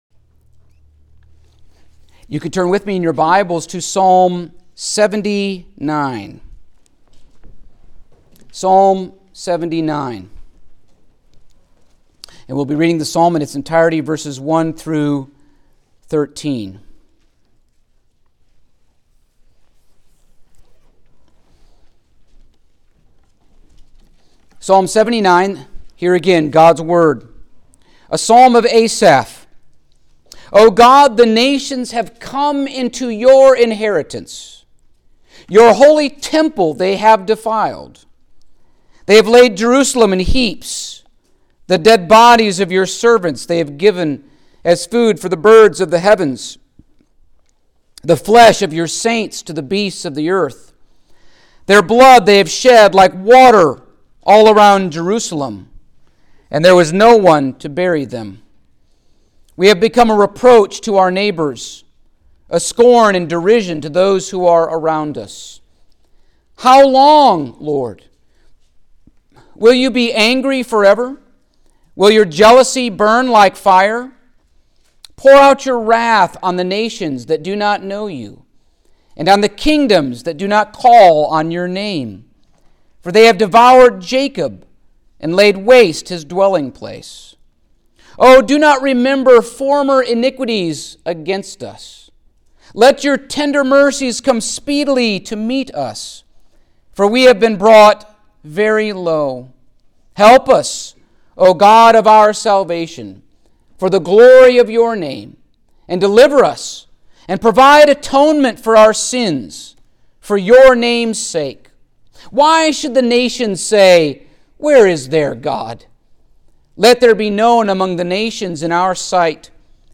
Passage: Psalm 79 Service Type: Sunday Morning